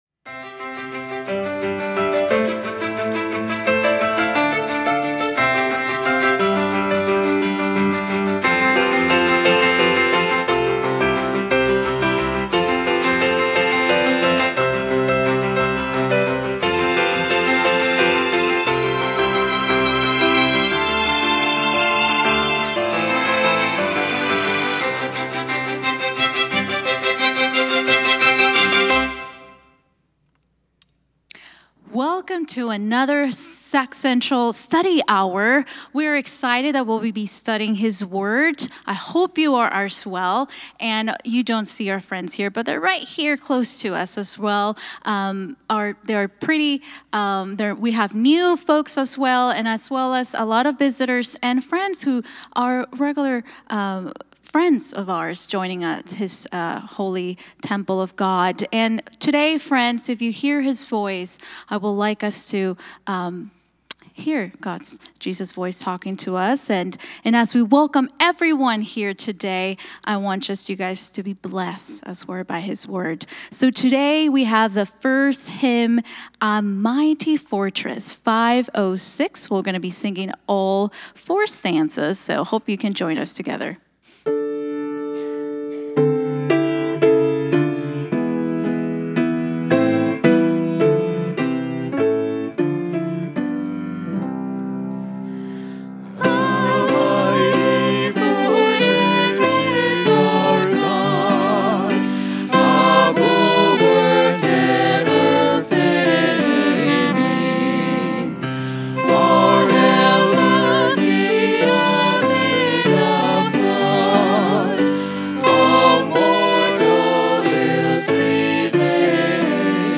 A message from the series "God's Love and Justice."